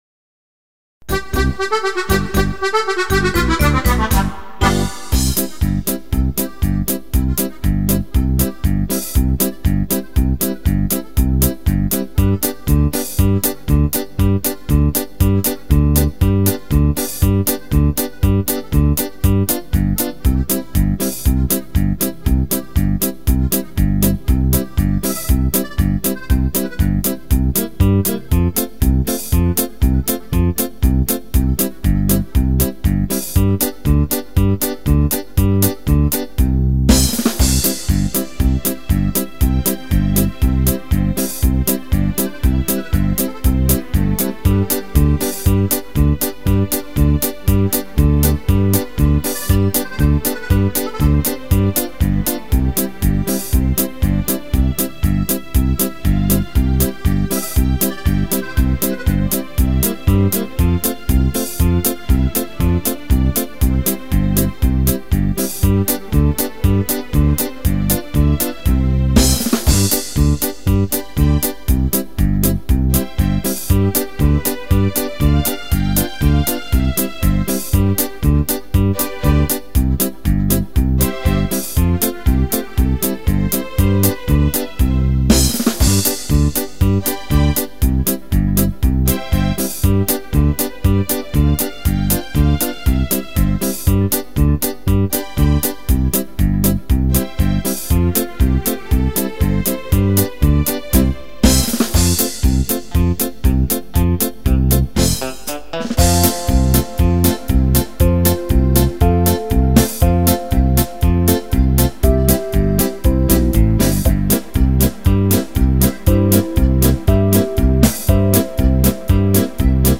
Canzoni e musiche da ballo
Polca